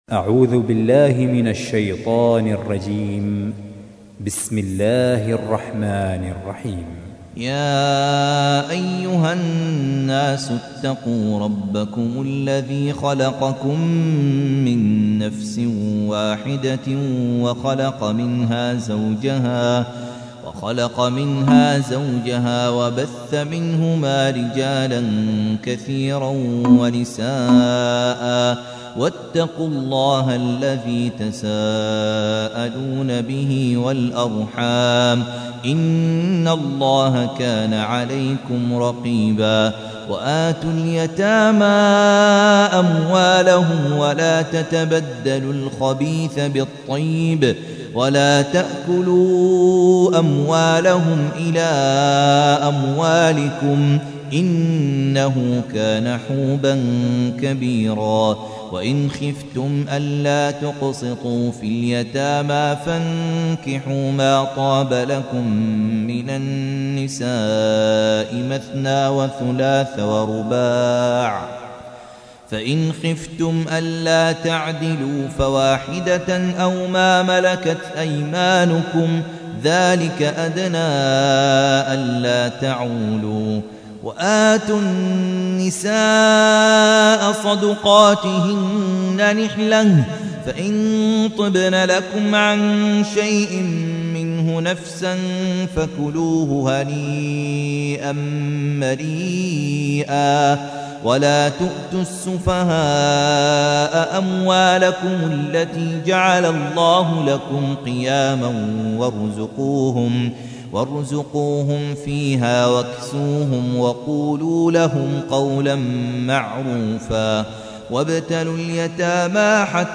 تحميل : 4. سورة النساء / القارئ خالد عبد الكافي / القرآن الكريم / موقع يا حسين